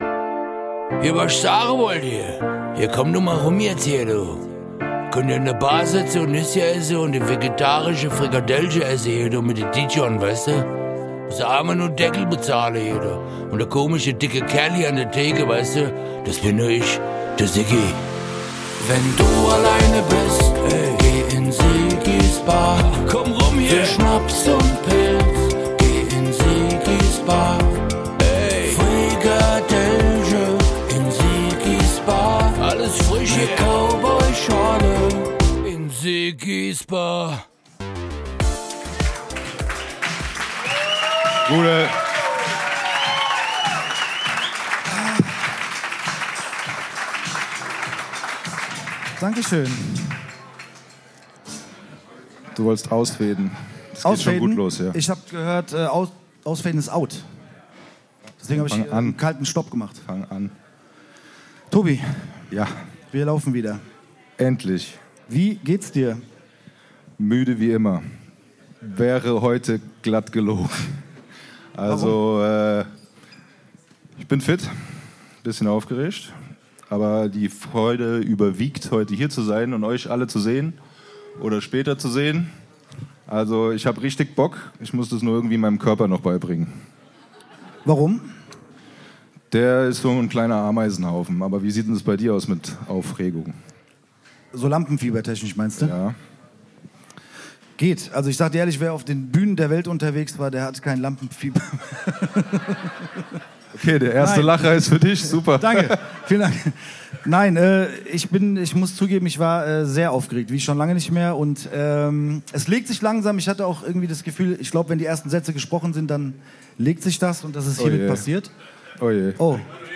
Die Jubiläumsfolge sogar LIVE im Massif Central.
Der hört sich anders.